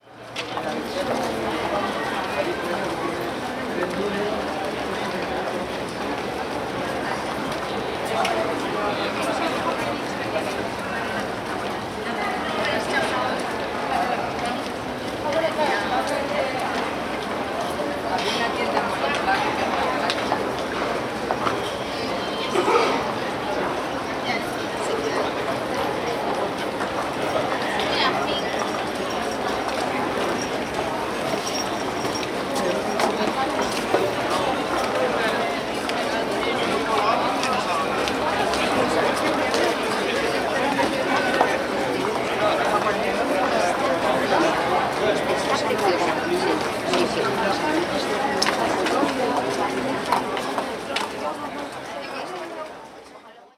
Ambiente de la calle Preciados, Madrid
voz viandante urbano tránsito transeúnte taconeo taconear tacón actividad ambiente andar paso peatón barullo bullicio calle charlar ciudad compra comprar confusión consumir consumo gente hablar murmullo ruido sonido
Sonidos: Gente Sonidos: Voz humana Sonidos: Ciudad